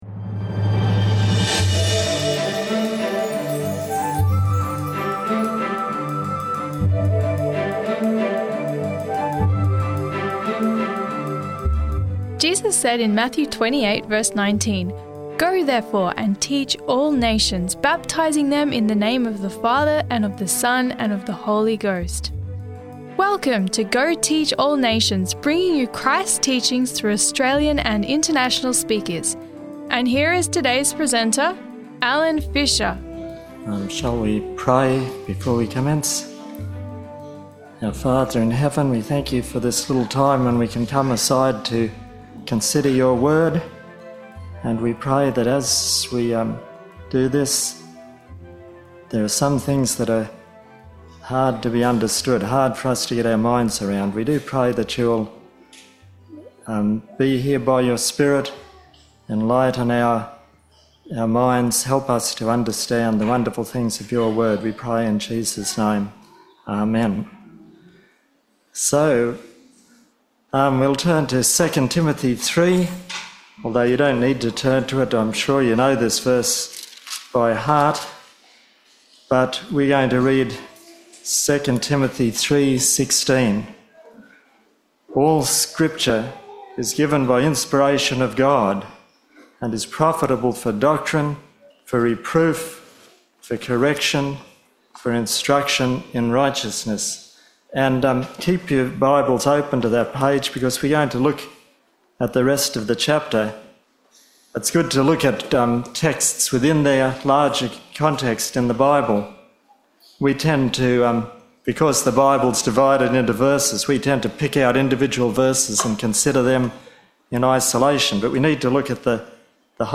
(Sermon Audio)